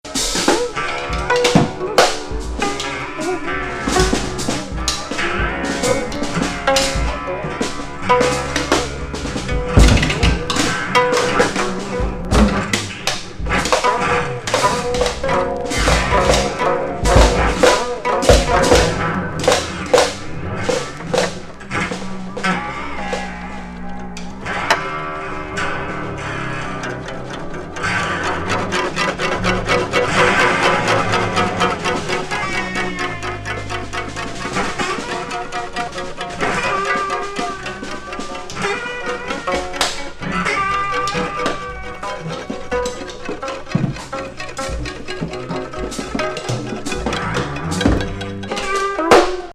芳醇なポロポロ・インプロ
プリミティヴな打楽器と、カヤグムの合奏にエレキ・